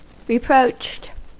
Ääntäminen
Ääntäminen US Haettu sana löytyi näillä lähdekielillä: englanti Käännöksiä ei löytynyt valitulle kohdekielelle. Reproached on sanan reproach partisiipin perfekti.